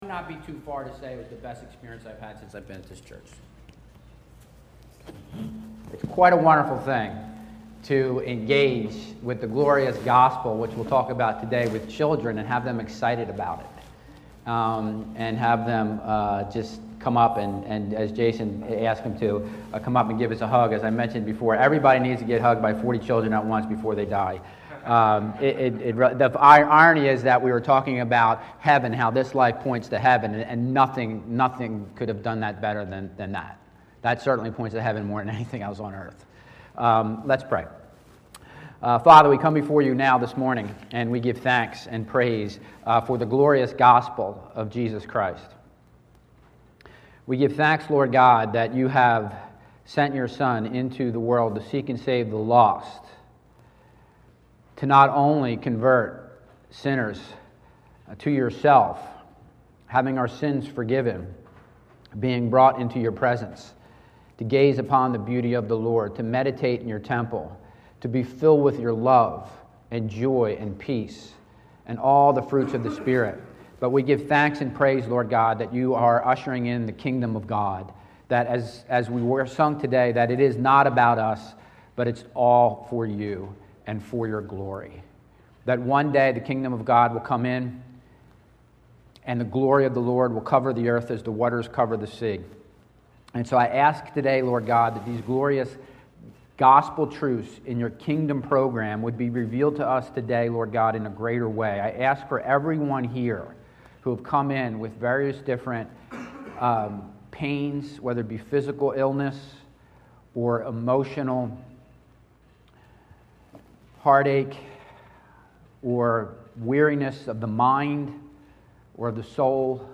Bible Text: Daniel 2 | Preacher